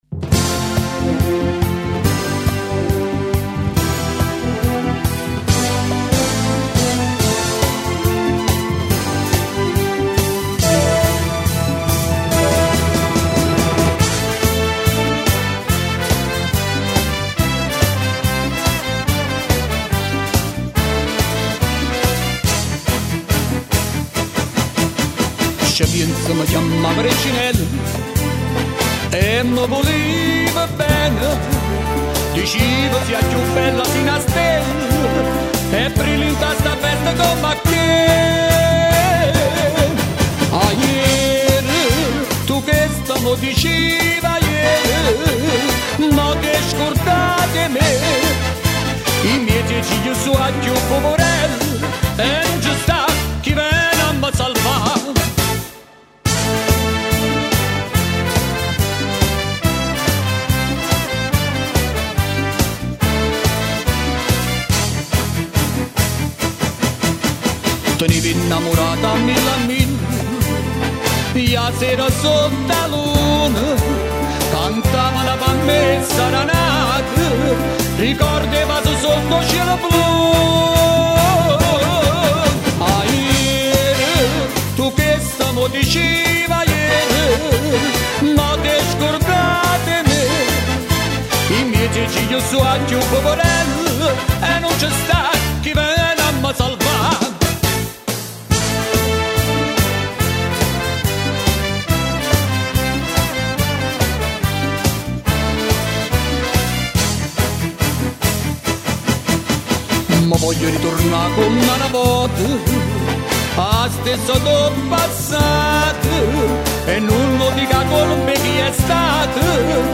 Divisione Musicale: De Angelis Band